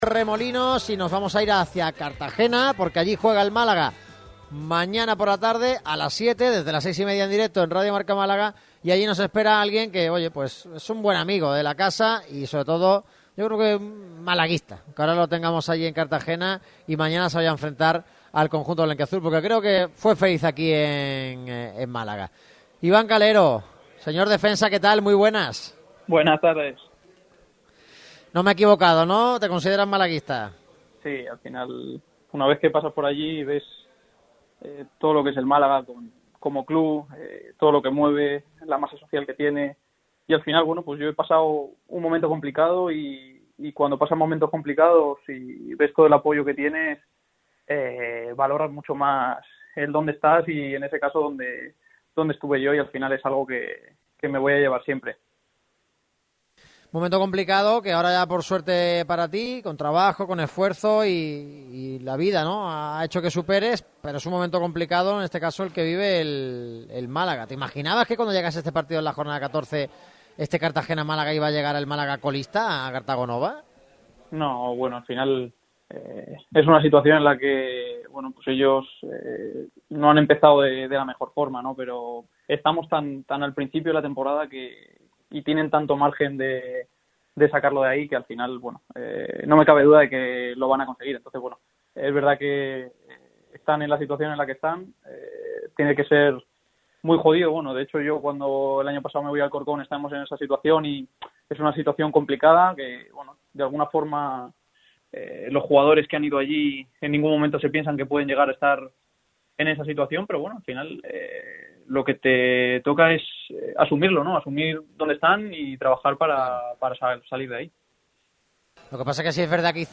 El ex malaguista, Iván Calero estuvo en una entrevista con Radio Marca Málaga.